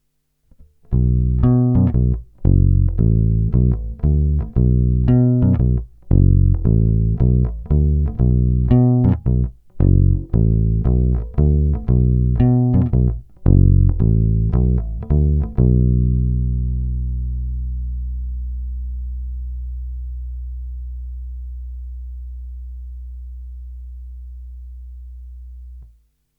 Zvuk asi mnohým sedět nebude, má výrazný kontrabasový charakter, kratší sustain, ale jinak je zamilováníhodný.
Není-li řečeno jinak, následující nahrávky jsou vyvedeny rovnou do zvukovky a kromě normalizace ponechány bez zásahů.
Hra u krku – zacloněno